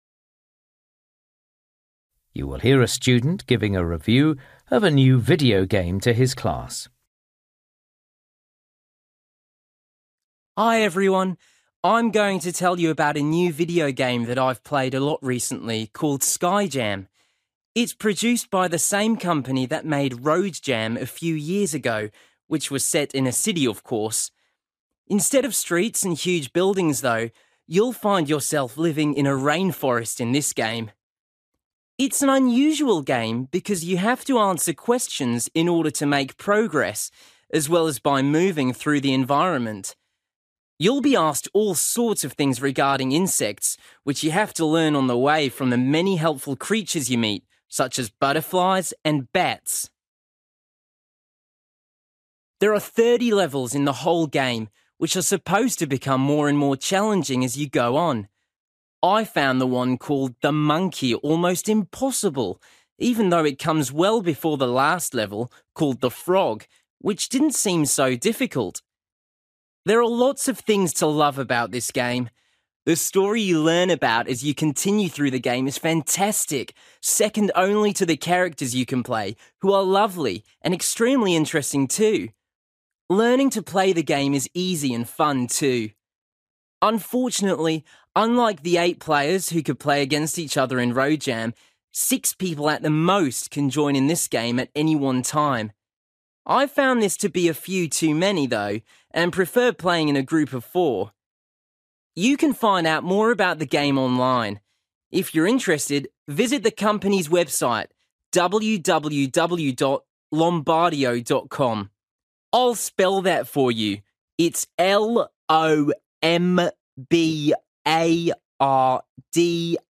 You will hear a student giving a review of a new video game to his class.